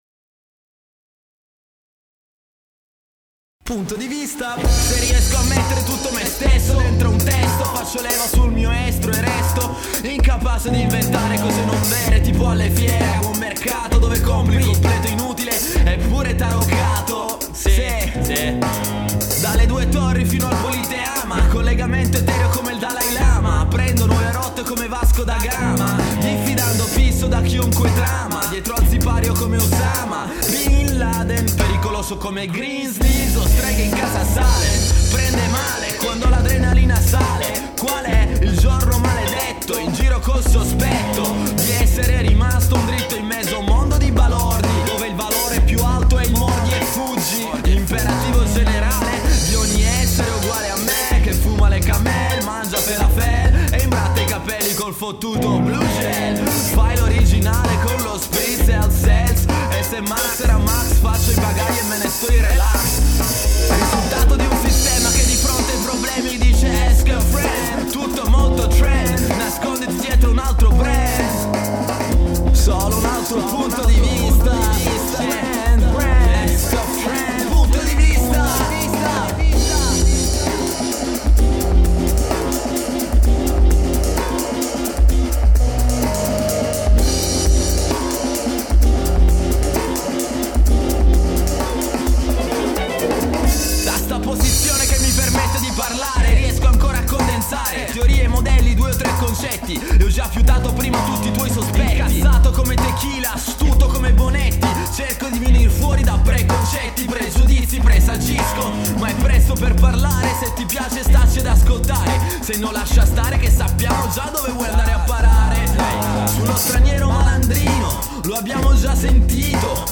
GenereHip Hop / Rap